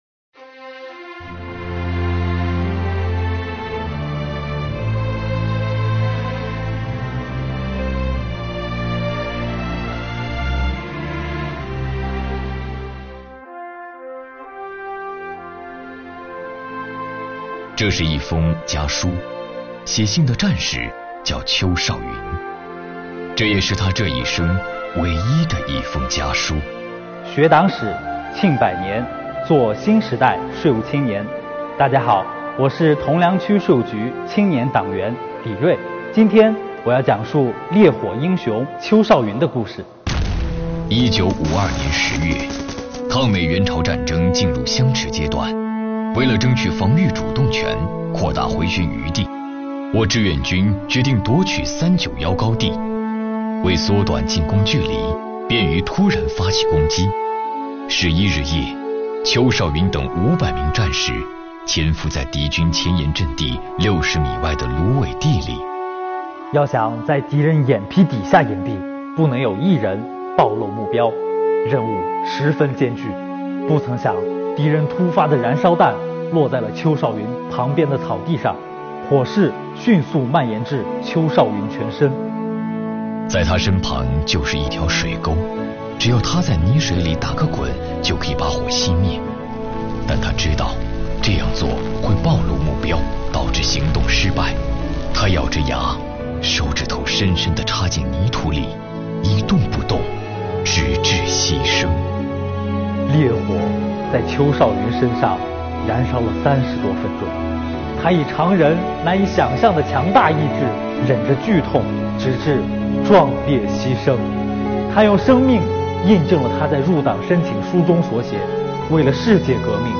听他讲述“烈火英雄”邱少云的故事。